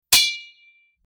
Sword Clash 02
Sword_clash_02.mp3